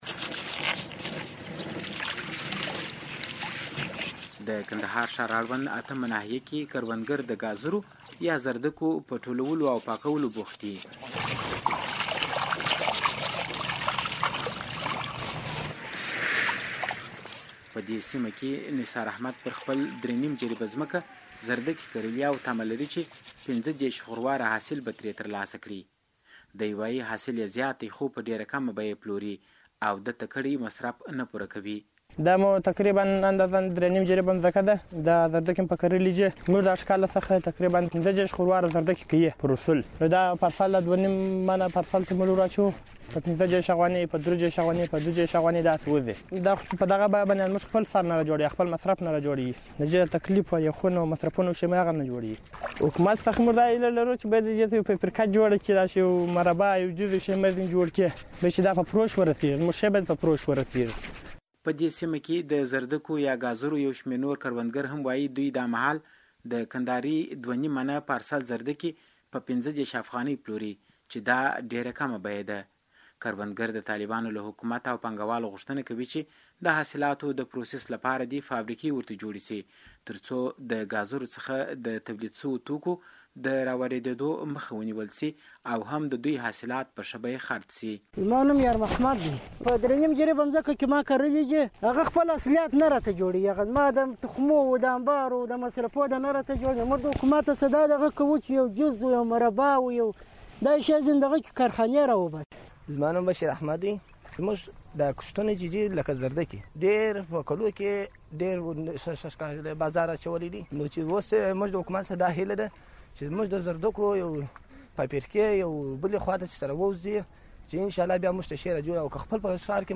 په دې اړه زموږ د همکار راپور د لاندې لېنک له کېکاږلو سره اورېدلی شئ:
د کندهار راپور